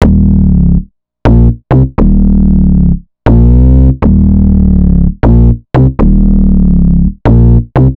Astro 5 Bass-D#.wav